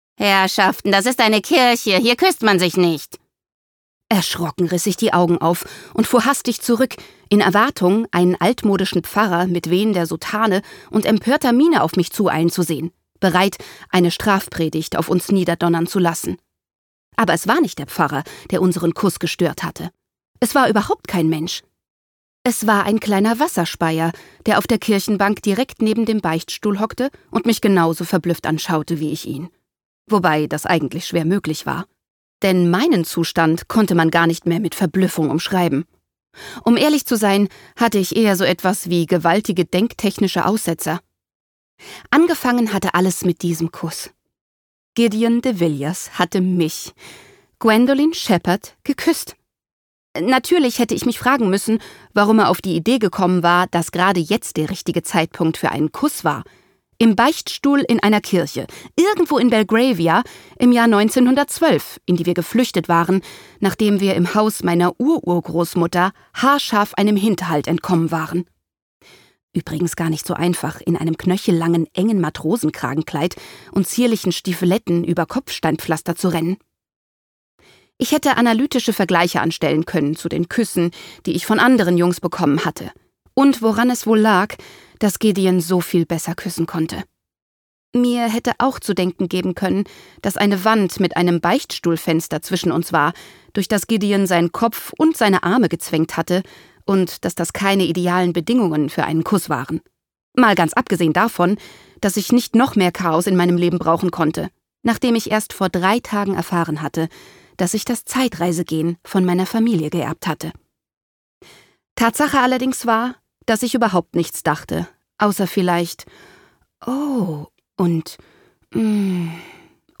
Der Name de Villiers wird in Übereinstimmung mit Kerstin Gier in diesem Hörbuch korrekt englisch ausgesprochen, da es sich zwar ursprünglich um ein französisches Geschlecht handelte, der englische Zweig der Familie aber mittlerweile englisch ausgesprochen wird.
Gekürzt Autorisierte, d.h. von Autor:innen und / oder Verlagen freigegebene, bearbeitete Fassung.